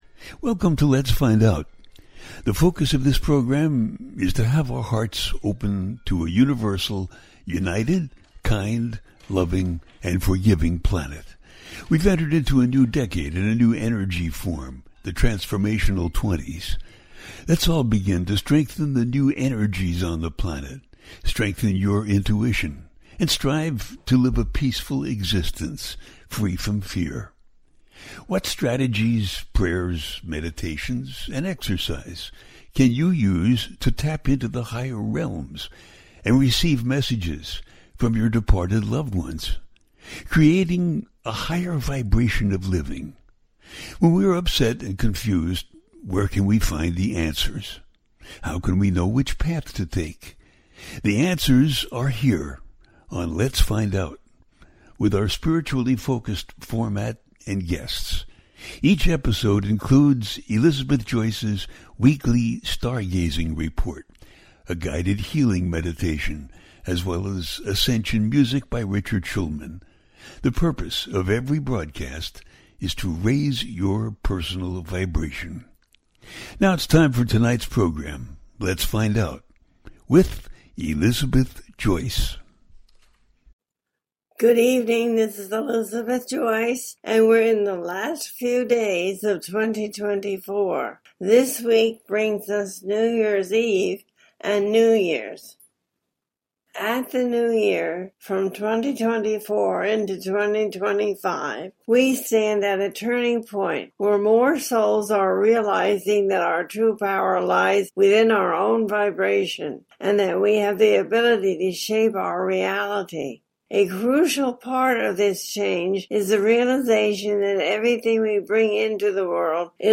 Talk Show Episode
2025 The Universal Connection - A teaching show
The listener can call in to ask a question on the air.
Each show ends with a guided meditation.